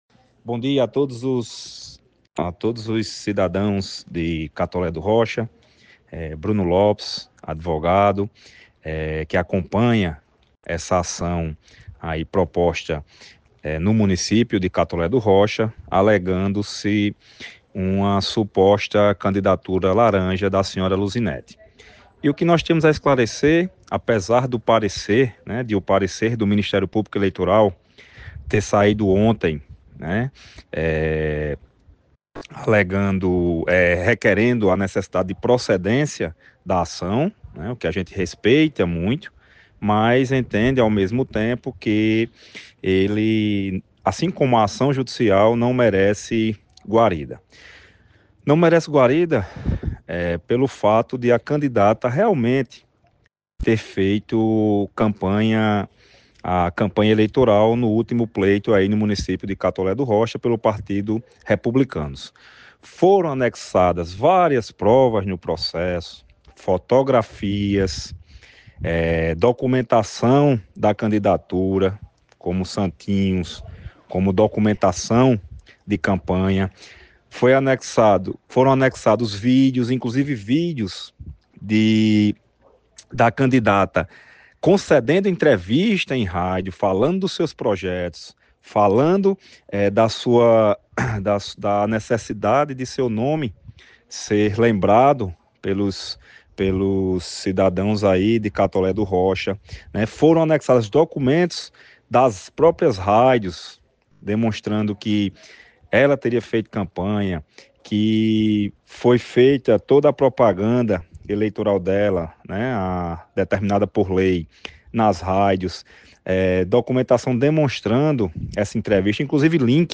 Manchetes